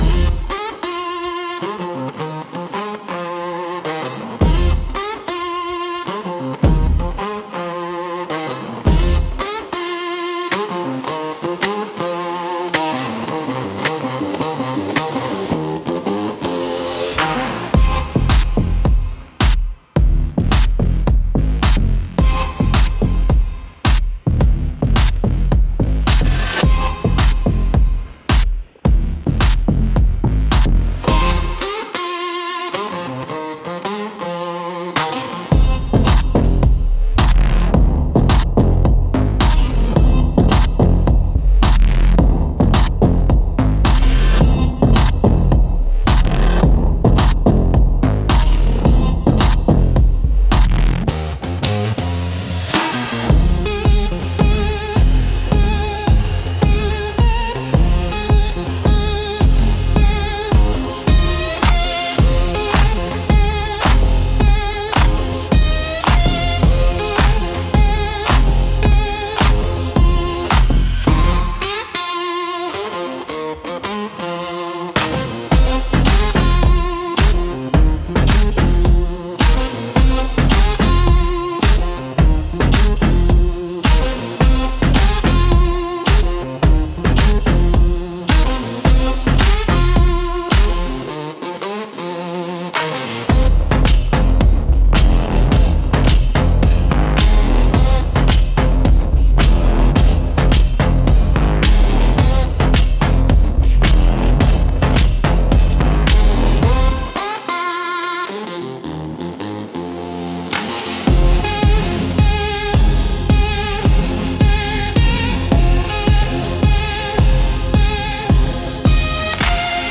Instrumental -3.04 Church Bells & Thunder